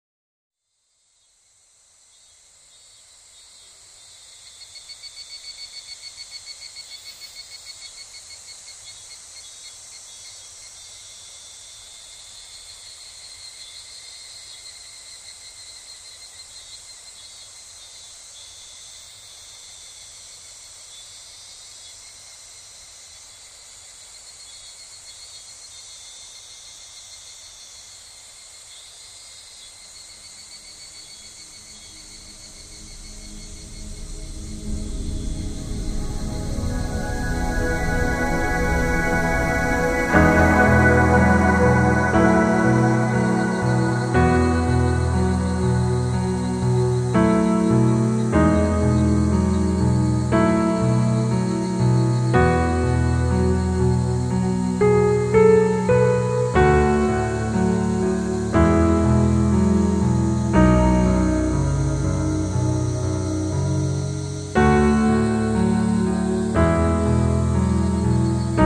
又或者别想任何困难的东西，放松，闭上眼，放平呼吸，让自己融入到这颂经声中去。